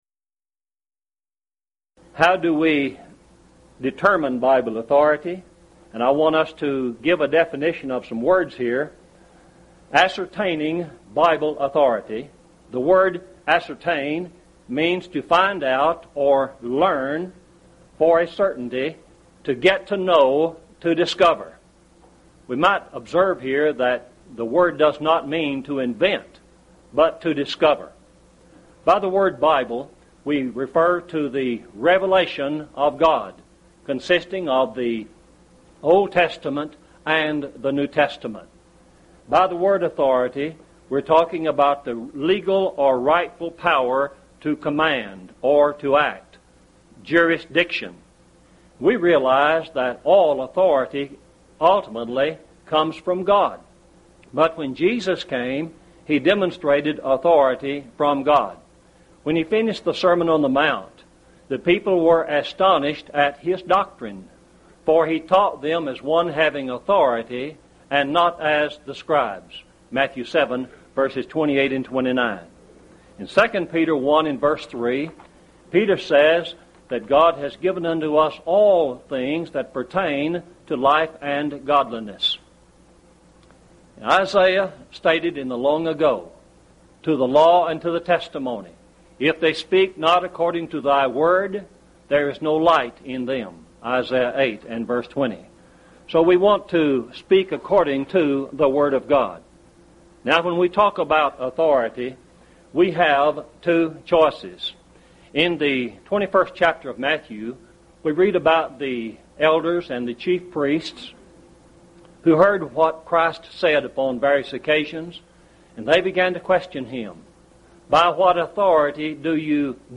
Event: 1st Annual Lubbock Lectures
lecture